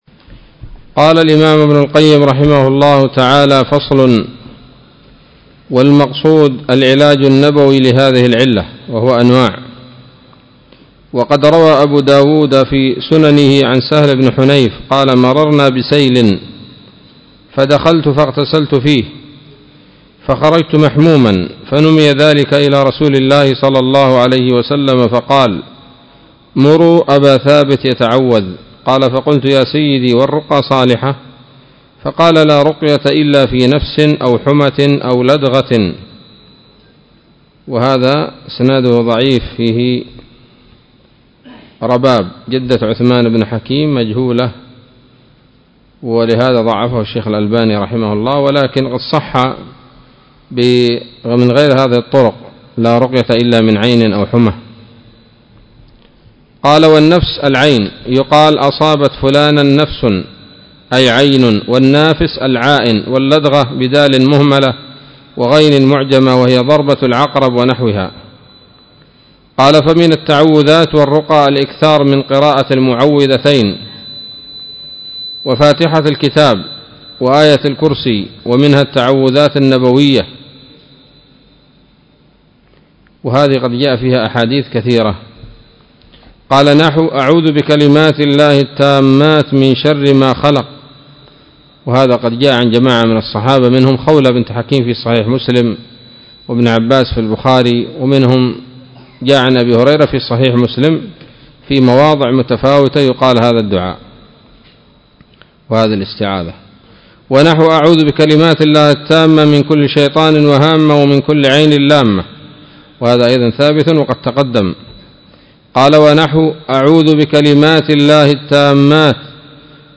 الدرس السابع والأربعون من كتاب الطب النبوي لابن القيم